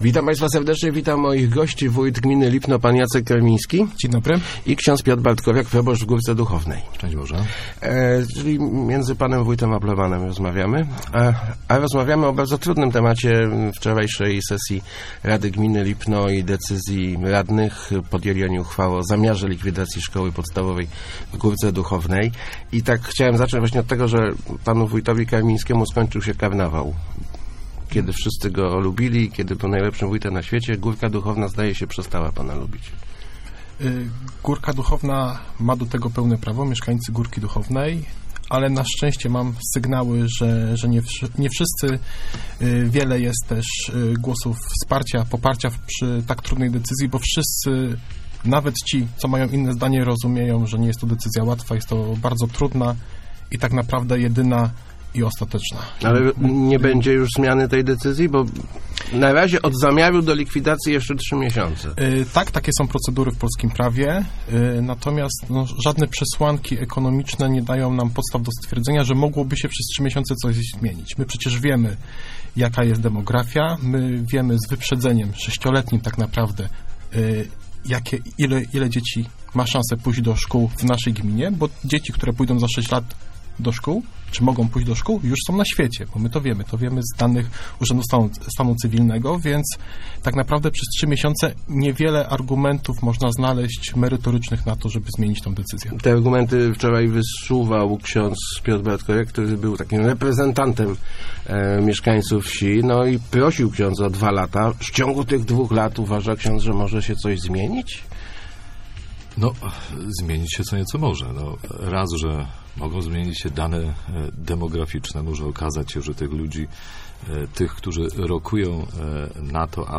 Najważniejszym problemem jest teraz odbudowanie więzi społecznych, które zostały mocno nadwyrężone przez decyzję o likwidacji szkoły w Górce Duchownej - mówił w Rozmowach Elki wójt gminy Lipno Jacek Karmiński.